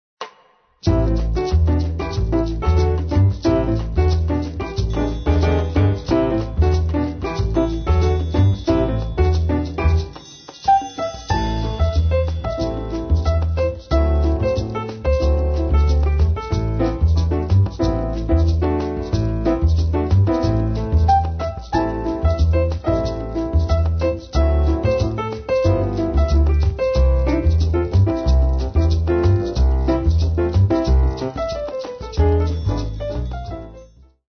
Détente